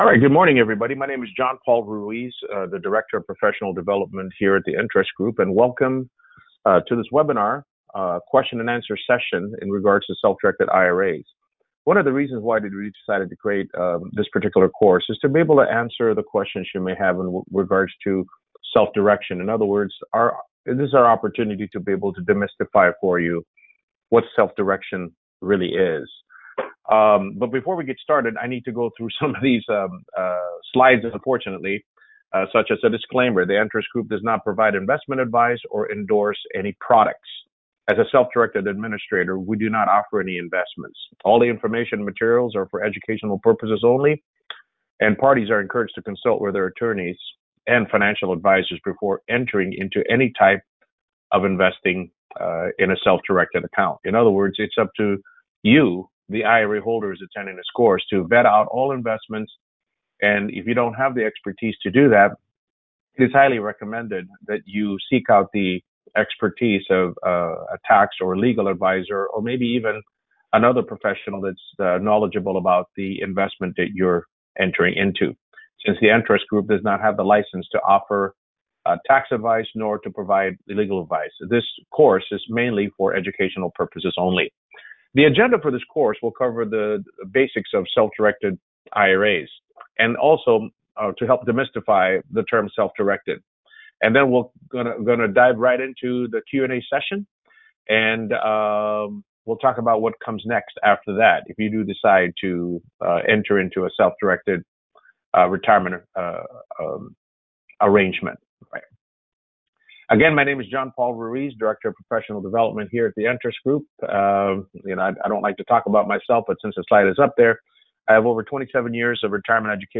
In this Q&A webinar replay, see what our investor audience is most curious about.